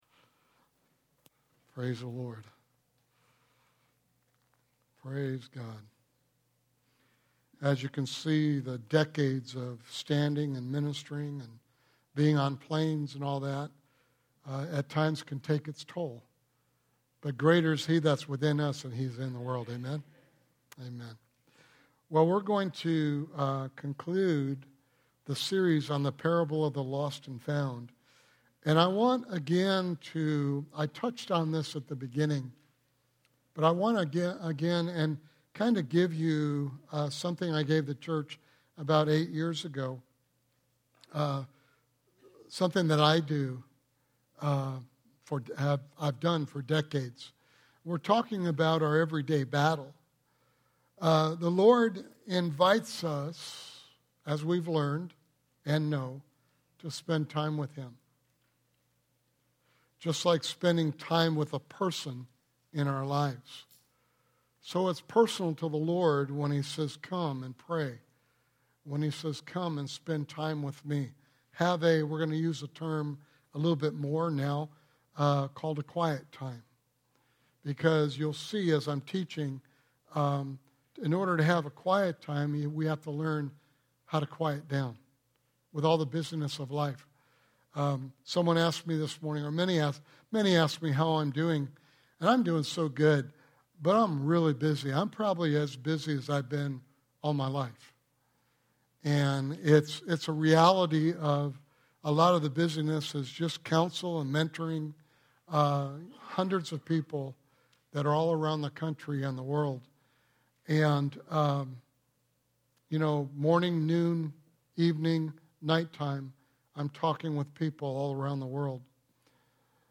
Sermon Series: The Parable of the Lost and Found